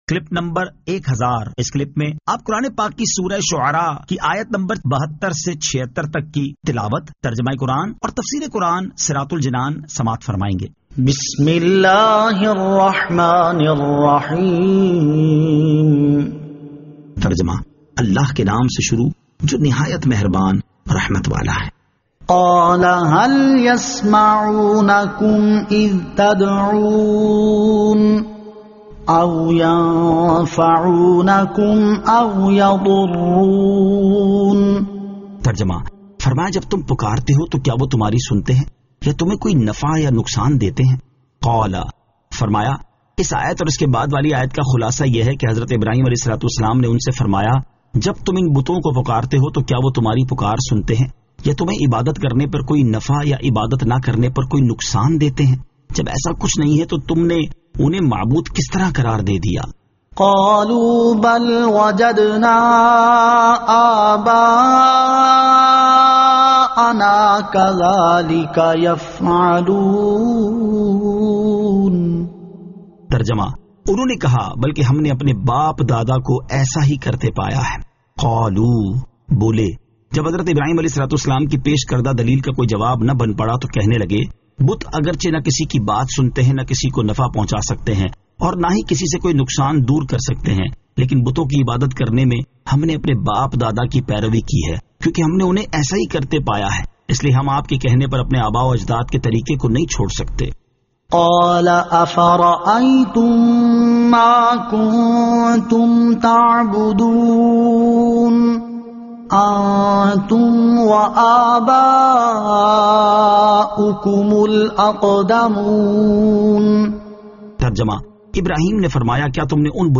Surah Ash-Shu'ara 72 To 76 Tilawat , Tarjama , Tafseer